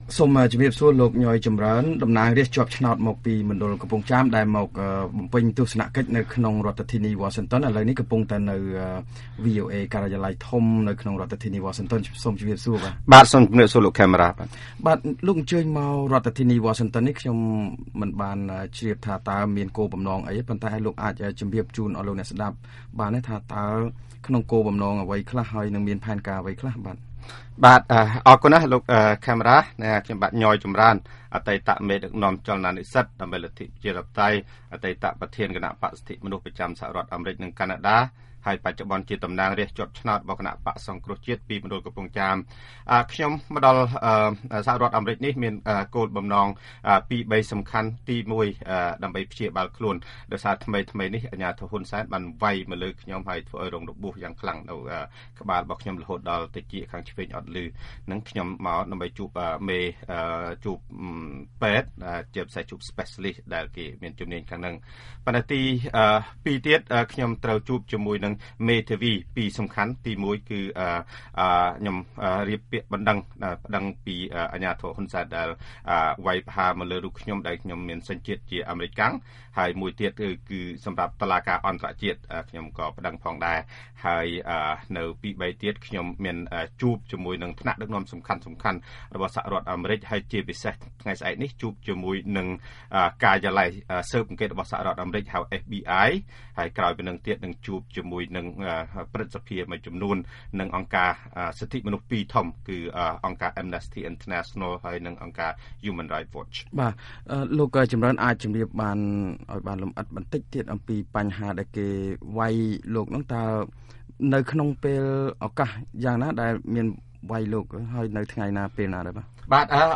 បទសម្ភាសន៍ជាមួយលោក ញ៉យ ចំរើន